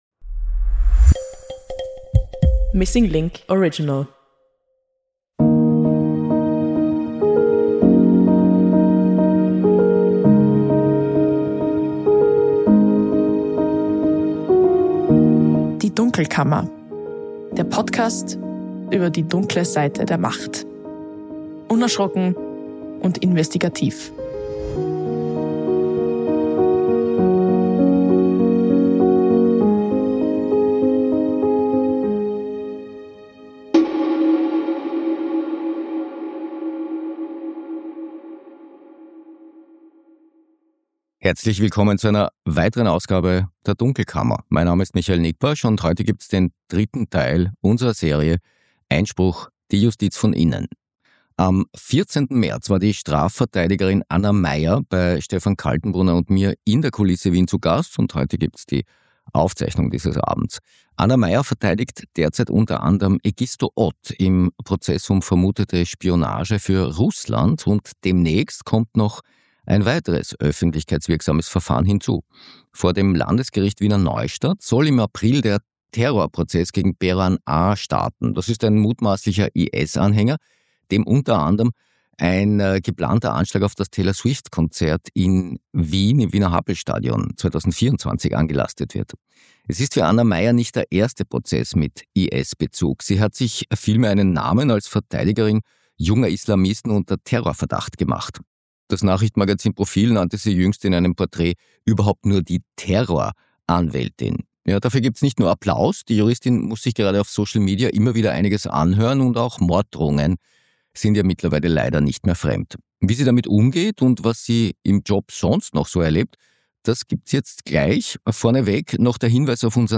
aufgezeichnet wurde am 14. März in der Kulisse Wien.